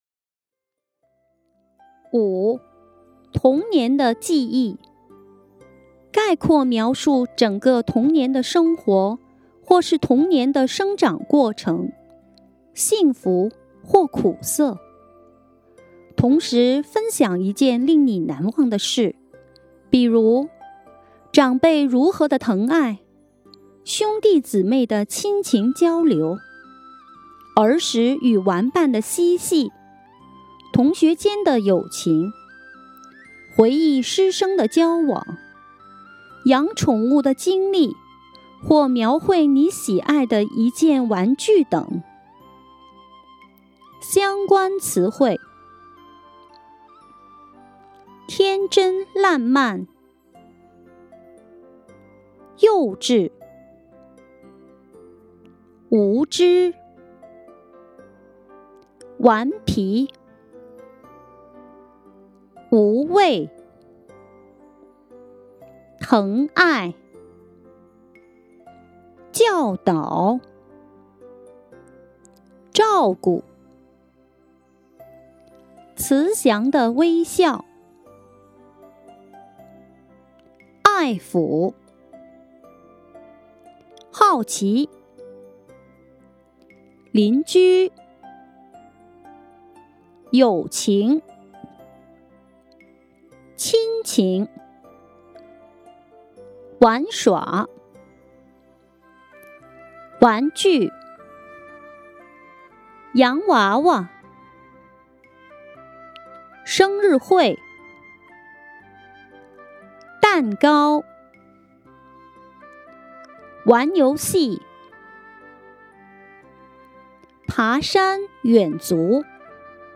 話題討論05 - 童年的記憶 21/04/09 17:10 Filed in: 國家普通話水平測試 | 話題討論 | 1-10 第五題《童年的記憶》語音參考 006: 童年的記憶 - d uration: 03:19 size: 4.8MB (mp3, stereo, 192kbps VBR) 文字檔案下載： Topic_05.pdf Tags: 國家普通話水平測試 , 童年 , 記憶 , 話題討論